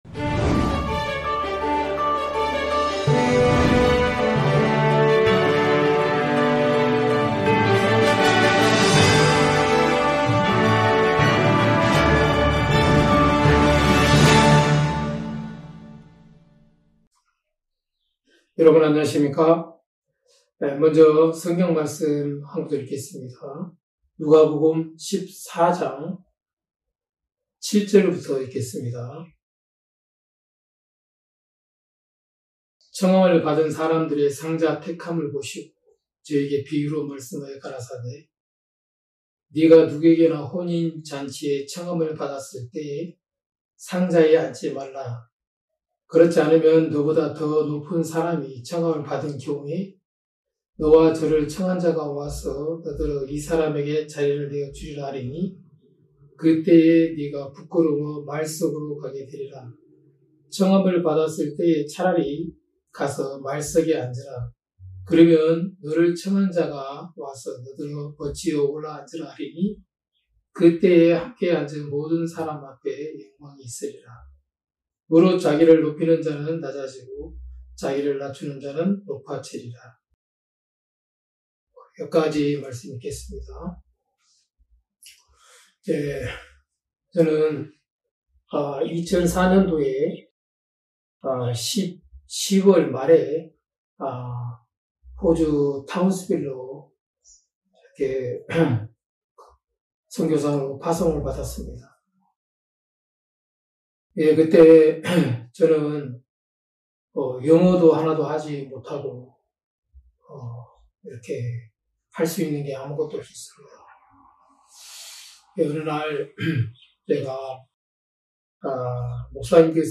매해 여름과 겨울, 일 년에 두 차례씩 열리는 기쁜소식선교회 캠프는 아직 죄 속에서 고통 받는 사람들에게는 구원의 말씀을, 일상에 지치고 마음이 무뎌진 형제자매들에게는 기쁨과 평안을 전하고 있습니다. 매년 굿뉴스티비를 통해 생중계 됐던 기쁜소식 선교회 캠프의 설교 말씀을 들어보세요.